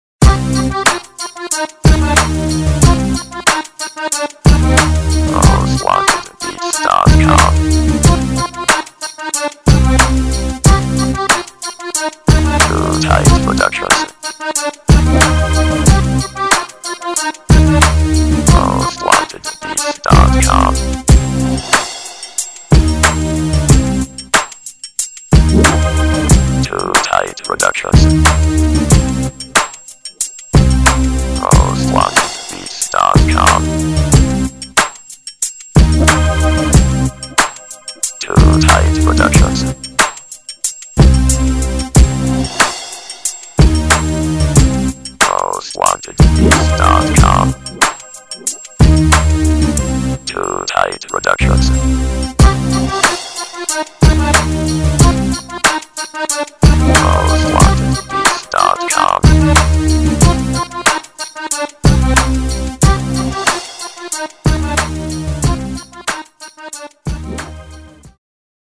HIP HOP INSTRUMENTAL